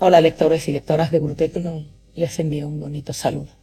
Probamos FakeYou para generar voces falsas de famosos, gracias a la Inteligencia Artificial
FakeYou-gurutecno-Rocio-Jurado.wav